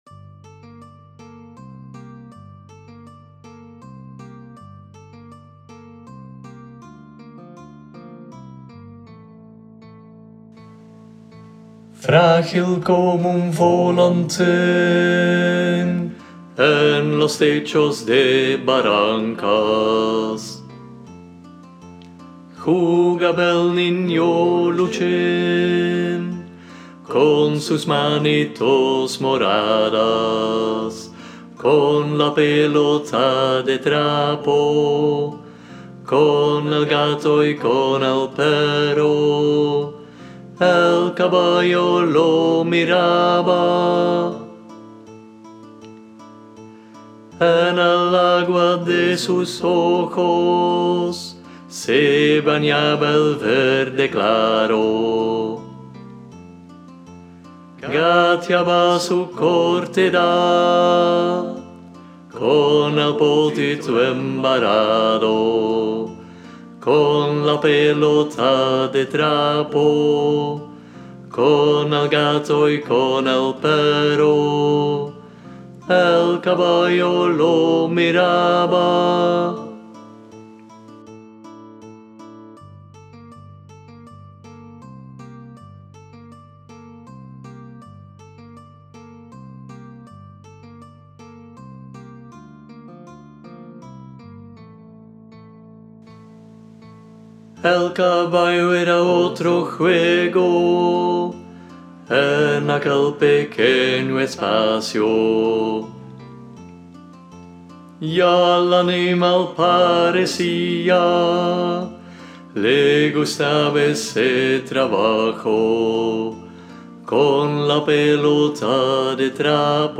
Luchín Alt.m4a